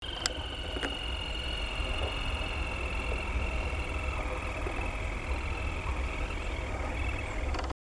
Rufous-sided Crake (Laterallus melanophaius)
Life Stage: Adult
Location or protected area: Parque Natural Municipal Ribera Norte (San Isidro)
Condition: Wild
Certainty: Recorded vocal